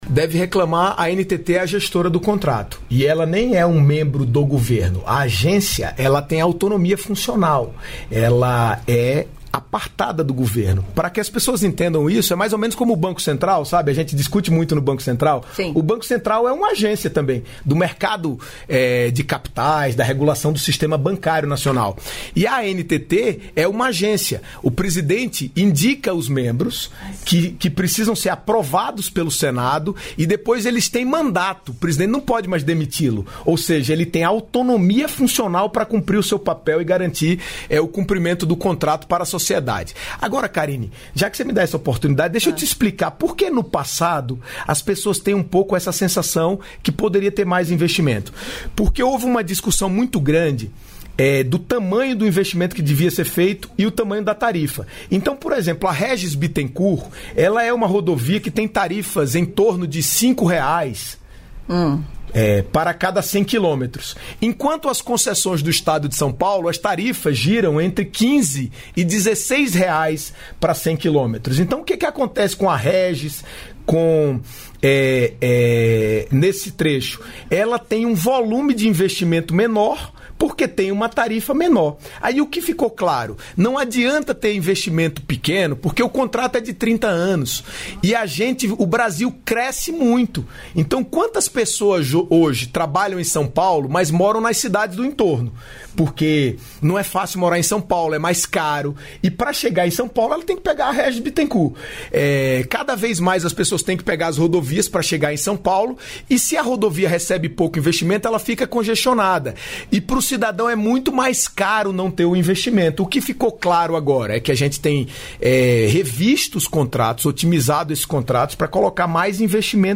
Trecho da participação do ministro dos Transportes, Renan Filho, no programa "Bom Dia, Ministro" desta quinta-feira (10), nos estúdios da EBC em Brasília (DF).